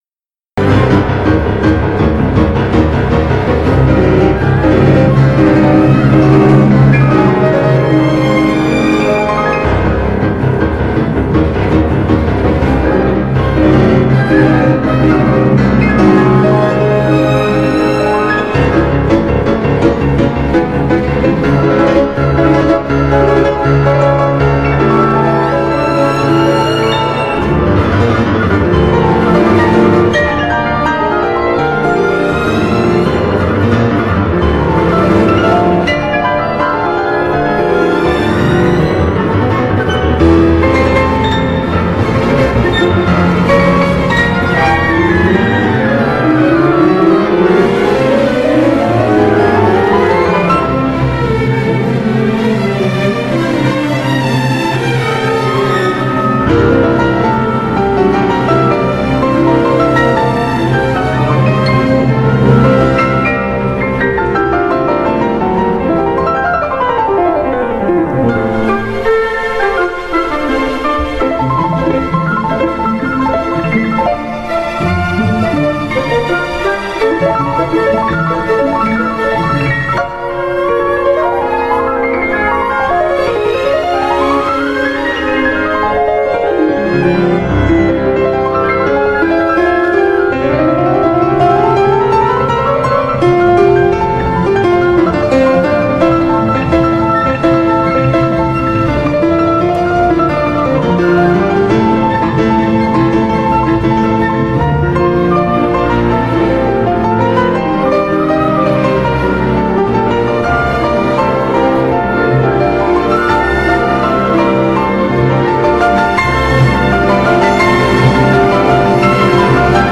پایان پیانو کنشرتوی 5 سن سانس .